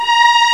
Index of /m8-backup/M8/Samples/Fairlight CMI/IIX/STRINGS1
VIOLHI2.WAV